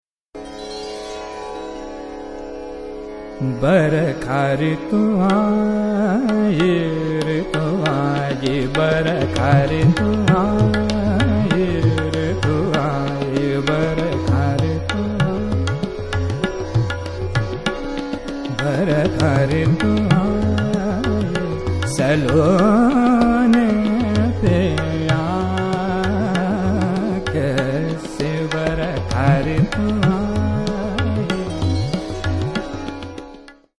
S-R-g-m-P-D-nN-S
Aroha: e.g. SRmPNS
Avroh: e.g. SnDPm, RgRS
• Tanpura: Sa–Pa